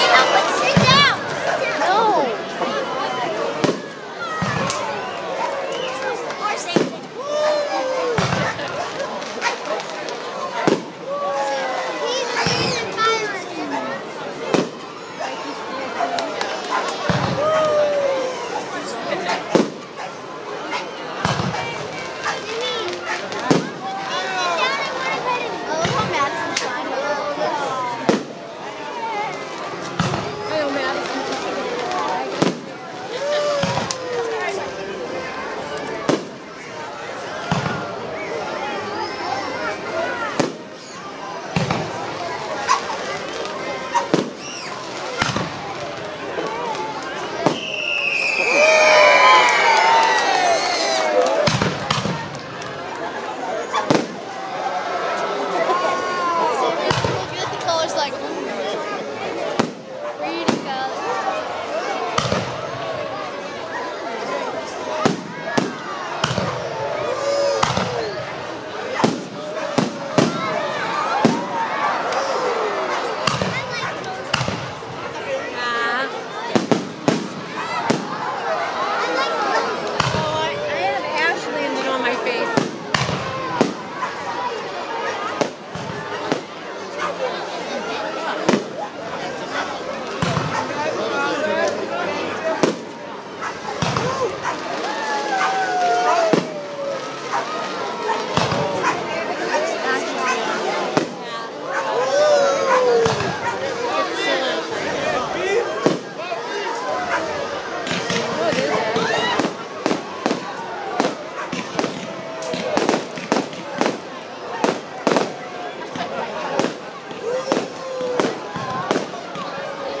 Trum Field Somerville Fireworks